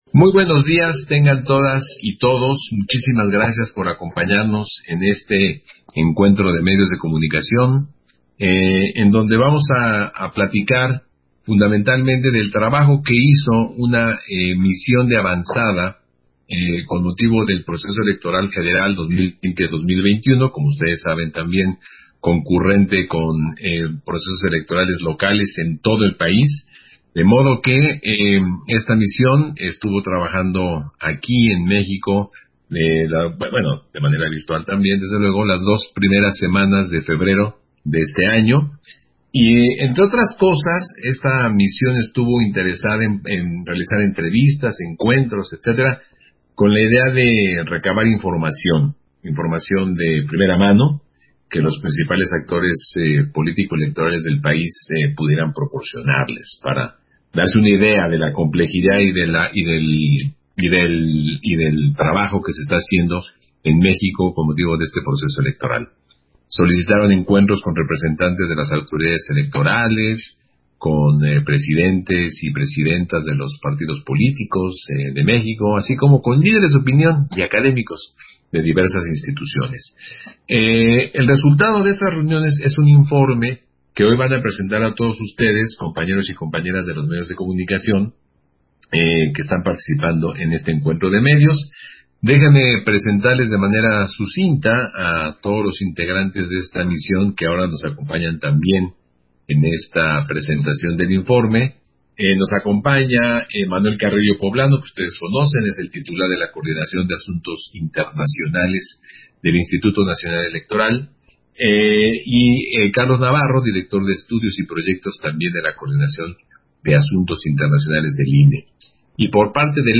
170321_AUDIO_CONFERENCIA-DE-PRENSA-MISIÓN-DE-ACOMPAÑAMIENTO